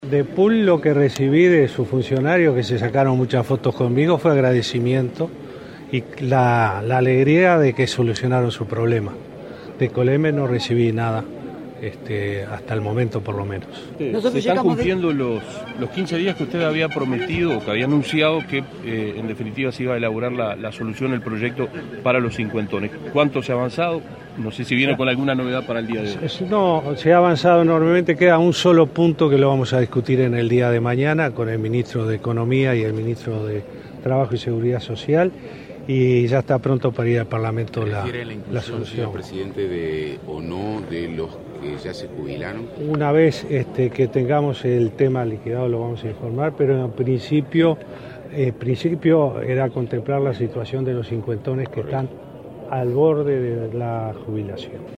“Los trabajadores del frigorífico Pul expresaron su agradecimiento y alegría por la solución de sus problemas”, informó el presidente de la República, Tabaré Vázquez, en declaraciones a la prensa, luego de dialogar con parte de ellos minutos antes del comienzo del Consejo de Ministros abierto en Ramón Trigo, Cerro Largo. También adelantó que este martes se terminará de elaborar el proyecto sobre jubilación para “cincuentones”.